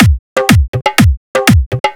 122 BPM Beat Loops Download